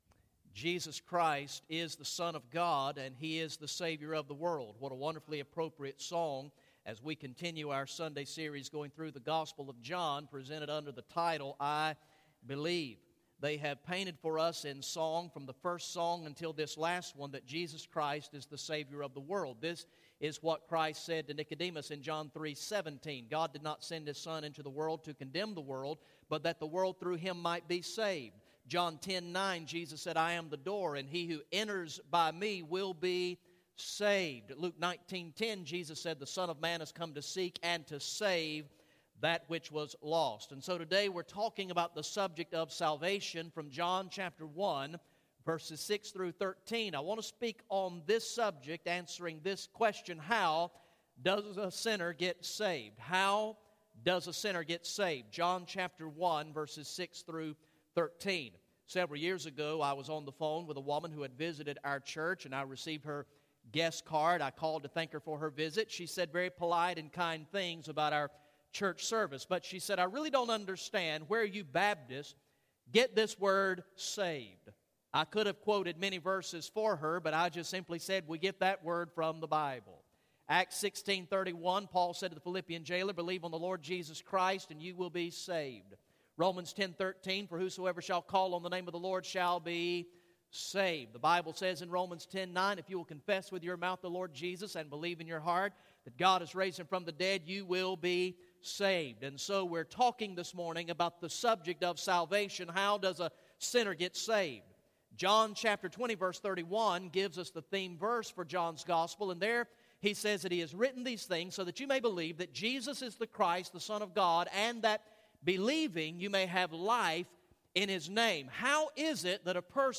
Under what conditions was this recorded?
Message #3 from the sermon series through the gospel of John entitled "I Believe" Recorded in the morning worship service on Sunday, February 23, 2014